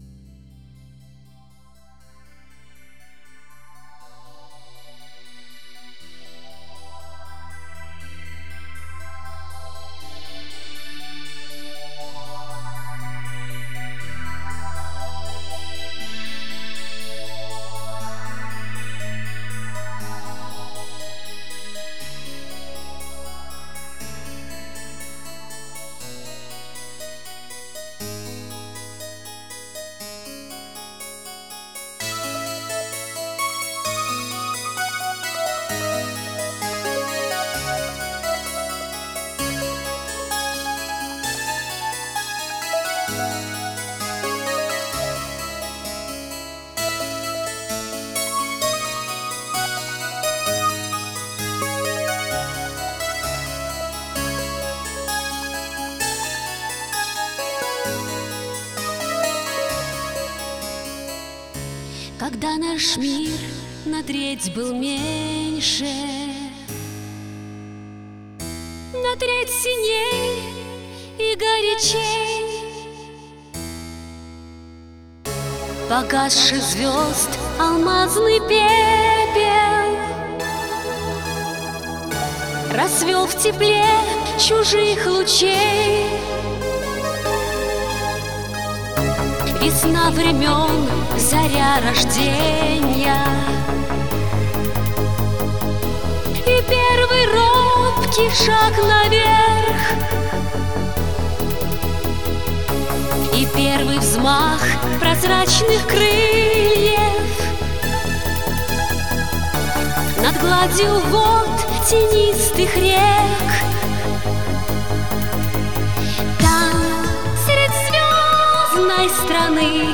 Техно-опера